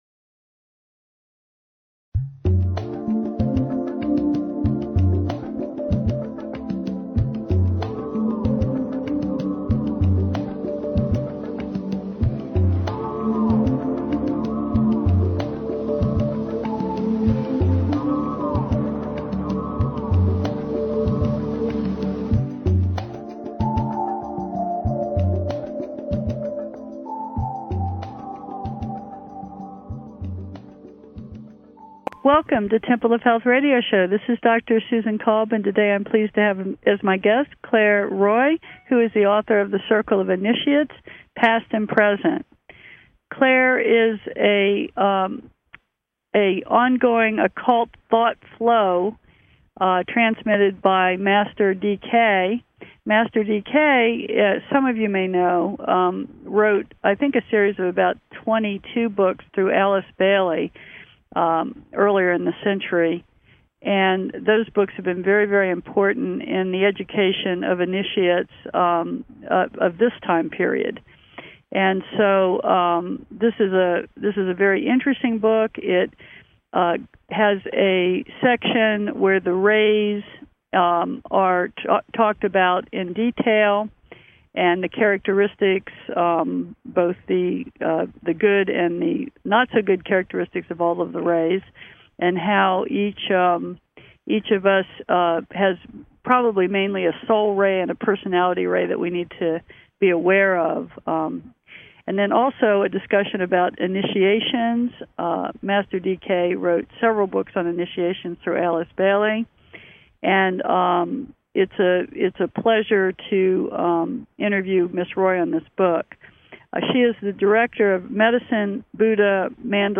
Temple of Health Radio Show , March 11, 2017